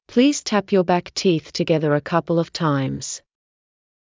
ﾌﾟﾘｰｽﾞ ﾀｯﾌﾟ ﾕｱ ﾊﾞｯｸ ﾃｨｰｽ ﾄｩｹﾞｻﾞｰ ｱ ｶｯﾌﾟﾙ ｵﾌﾞ ﾀｲﾑｽ